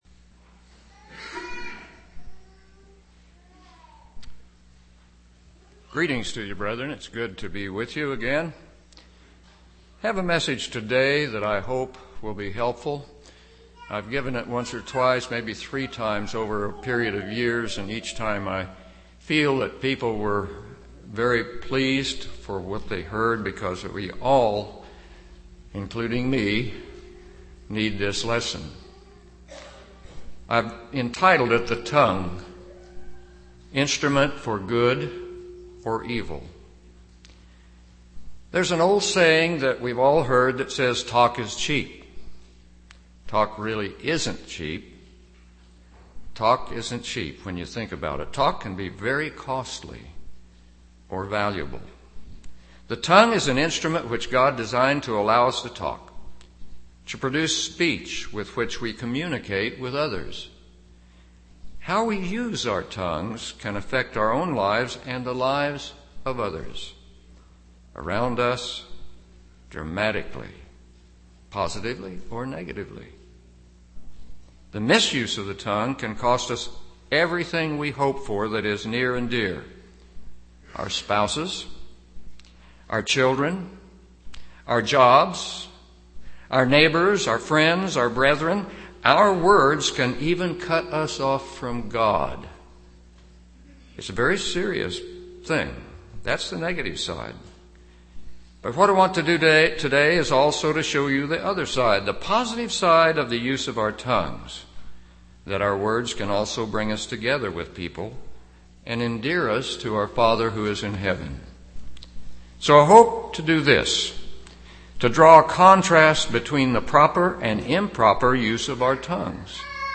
These are the notes taken live during services as captioning for the deaf and hard of hearing.
UCG Sermon Notes These are the notes taken live during services as captioning for the deaf and hard of hearing.